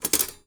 FOLEY_Toaster_Eject_mono.wav